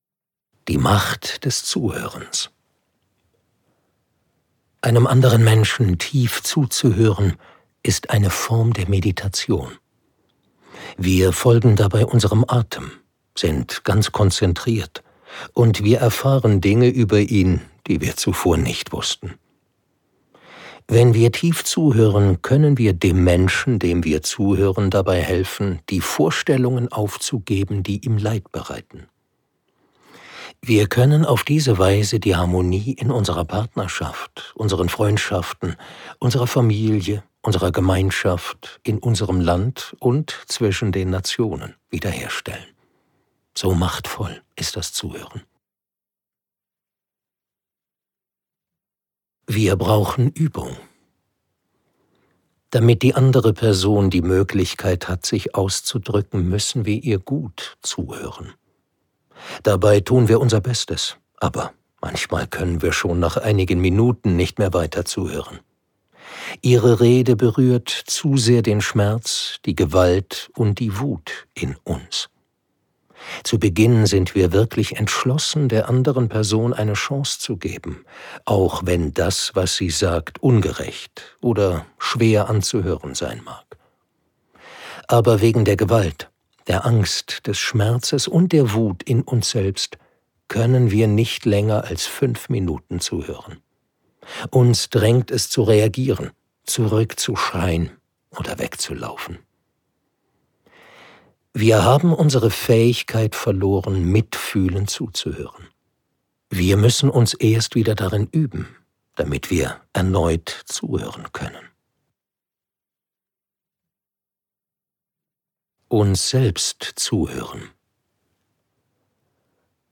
Einfach zuhören - Thich Nhat Hanh | argon hörbuch